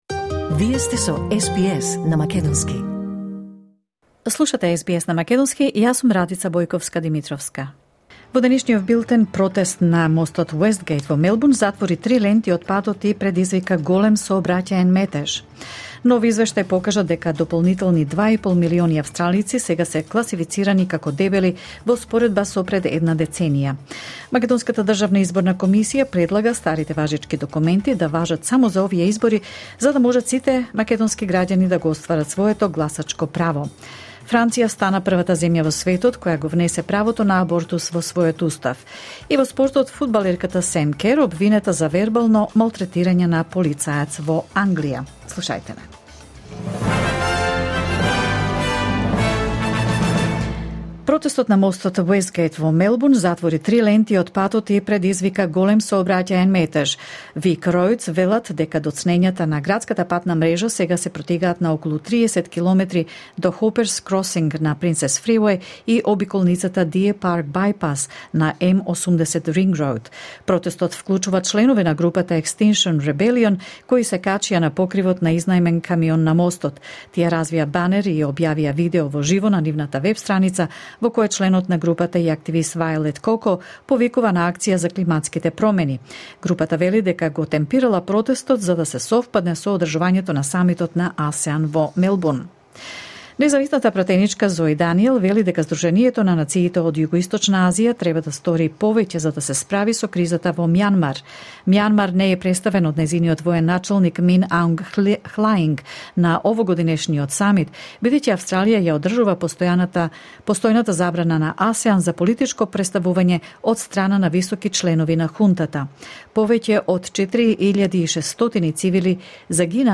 SBS News in Macedonian 5 March 2024